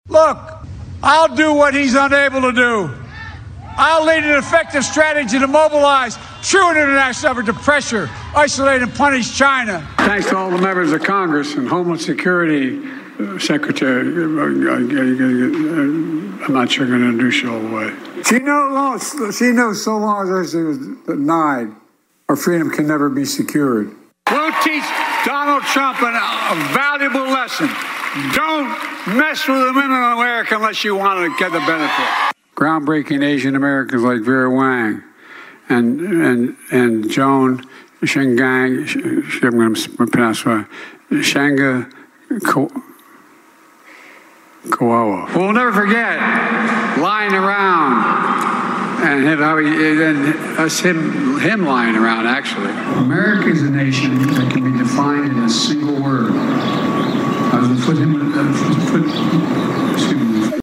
JOE'S GIBBERISH: A Montage of Priceless and Unintelligible Biden Moments [LISTEN]
BIDEN-UNINTELLIGIBLE-GAFFES-MONTAGE.mp3